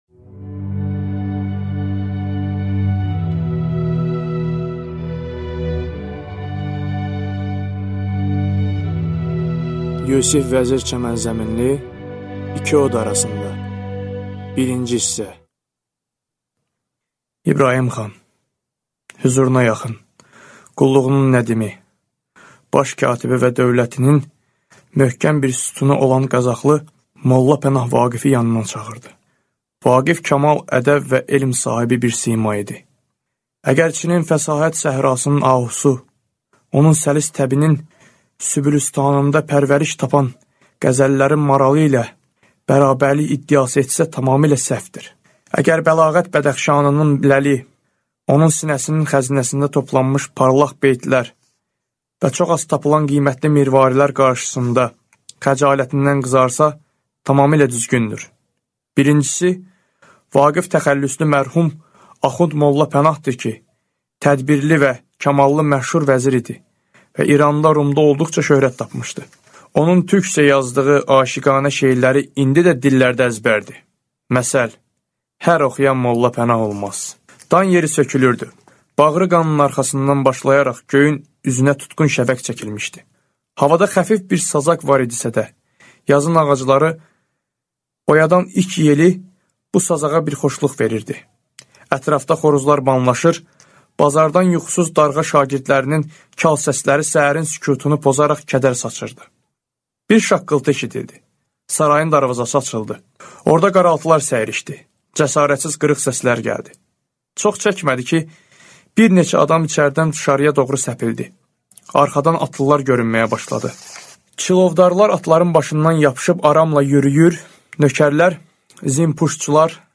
Аудиокнига İki od arasında | Библиотека аудиокниг